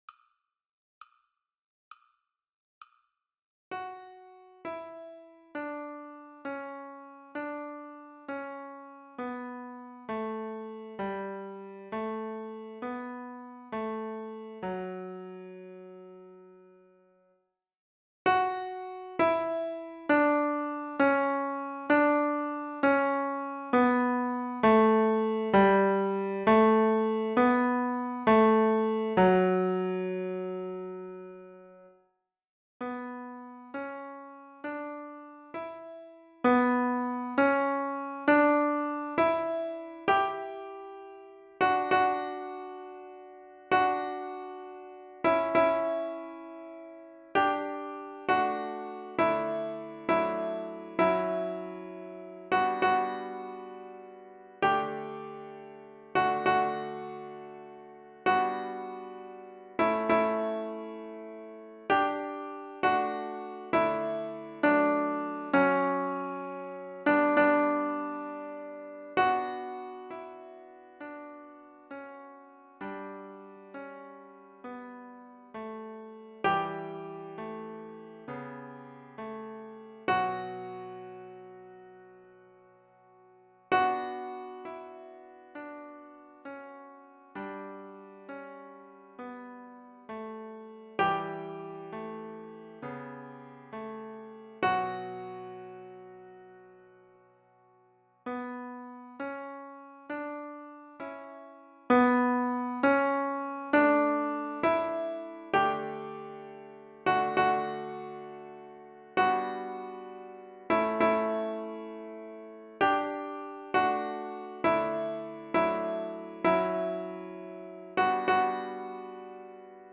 GIvs5q33D3p_juste-un-peu-de-silence-chorale-Soprano.mp3